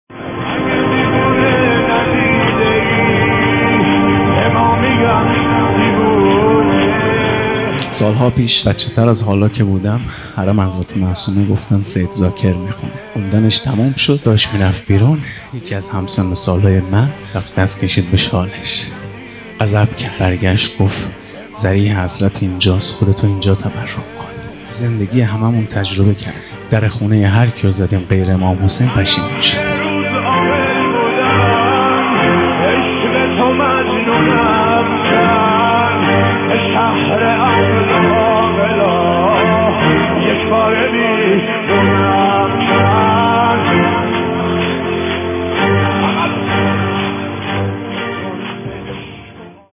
وفات حضرت ام البنین (س) 98 | هیئت لوا الزینب (س) قم